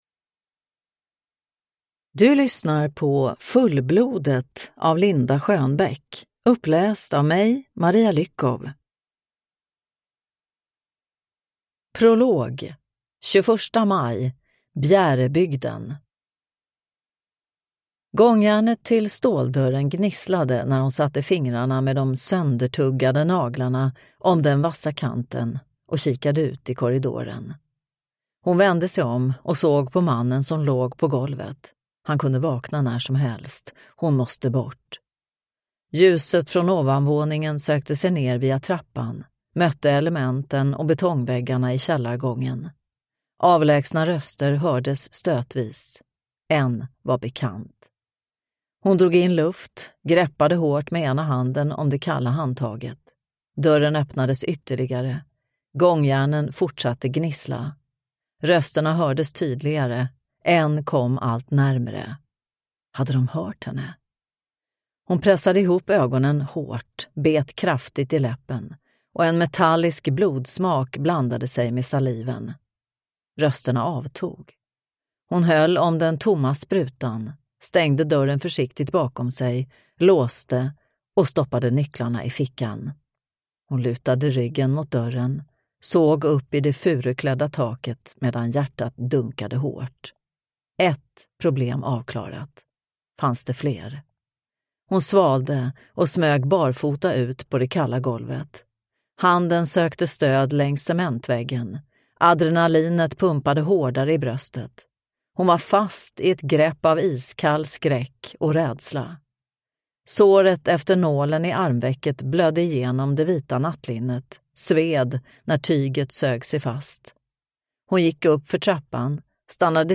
Fullblodet (ljudbok) av Linda Schönbeck